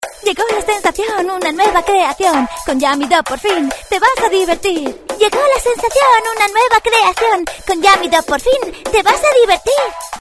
grabación de canción para publicidad.